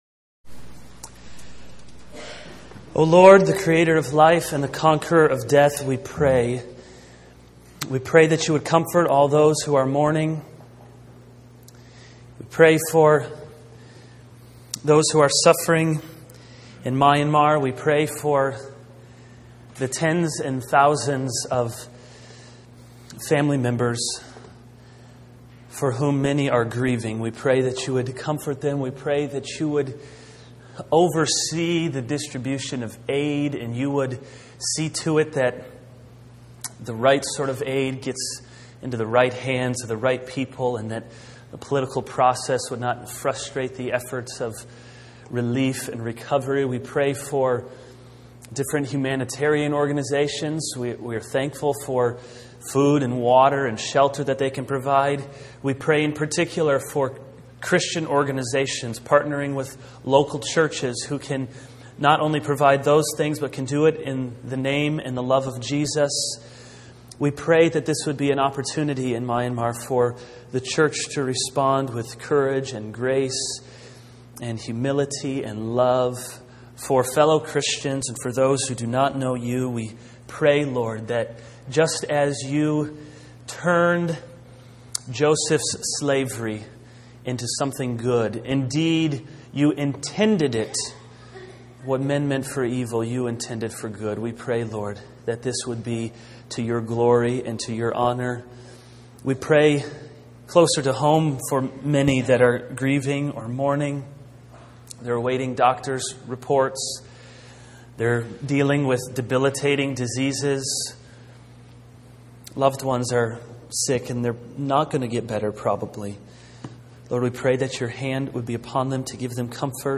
This is a sermon on 2 Corinthians 5:1-5.